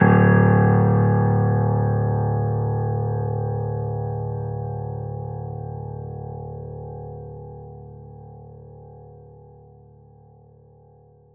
piano-sounds-dev
Vintage_Upright